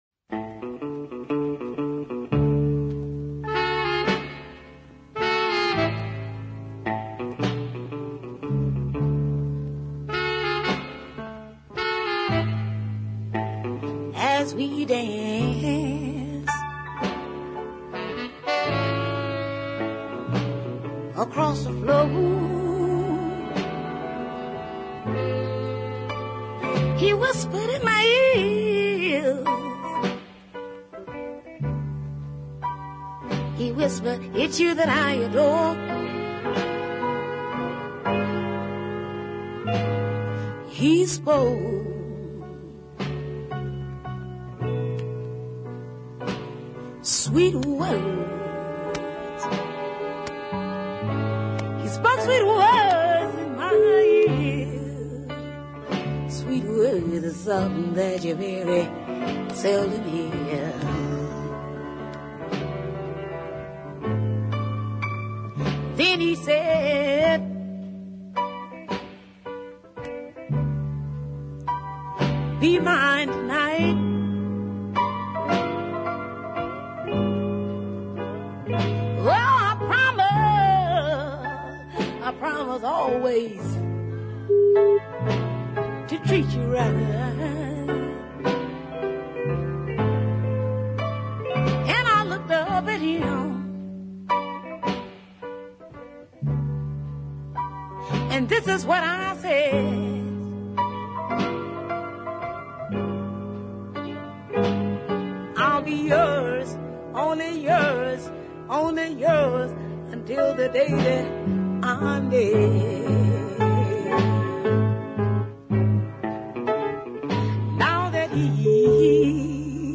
hard soul
tough no nonsense vocals
southern styled throw back productions
piano/horn arrangement